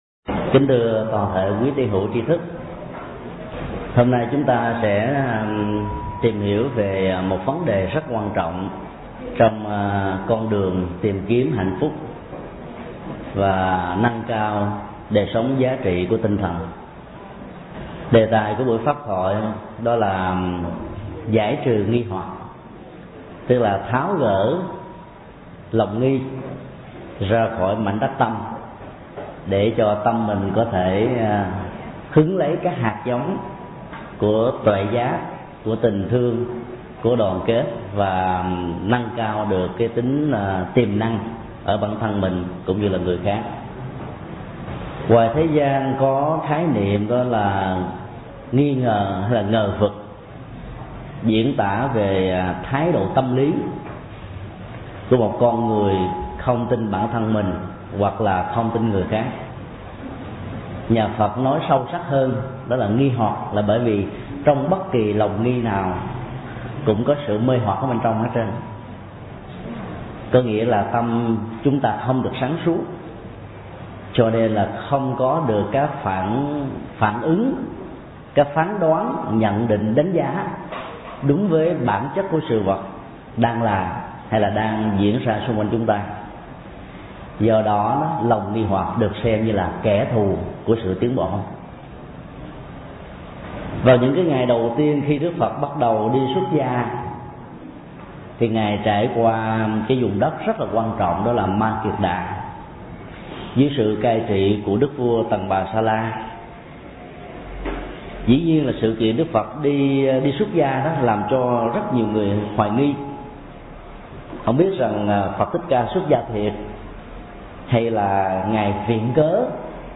Tải mp3 Thuyết Giảng Giải trừ nghi hoặc – Thầy Thích Nhật Từ Giảng tại Tịnh xá Trung Tâm, ngày 8 tháng 5 năm 2005